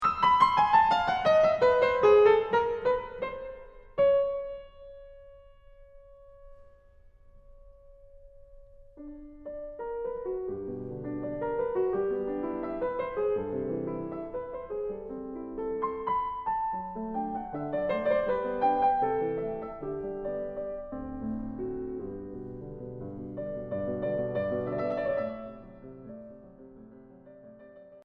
However, the very last notes of the development is so charming and ends as if to have us think that it was merely a little detour on our sunny, pastoral trip through this movement.